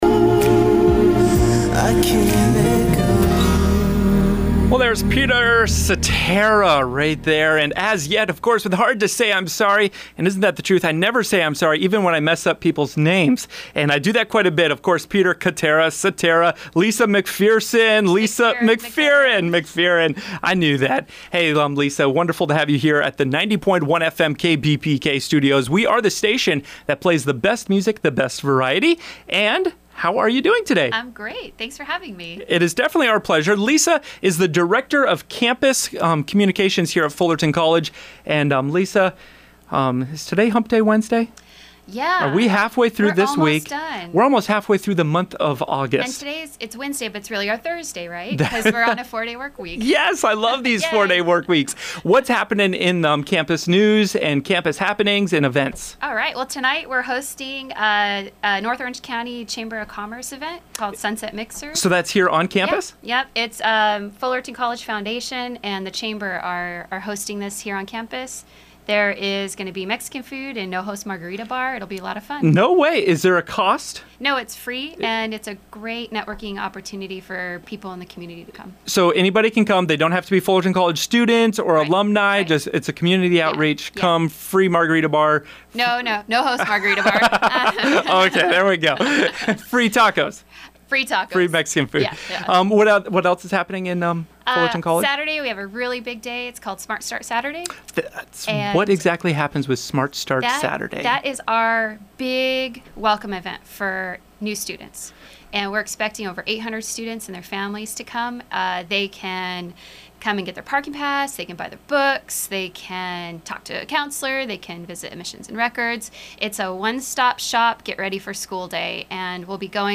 With a few laughs, the two discussed the Sunset Mixer on Aug. 12 hosted by the North Orange County Chamber of Commerce and the Fullerton College Foundation. They also talked about Smart Start Saturday on Aug. 15 and Fall 2015 Convocation on Aug. 21.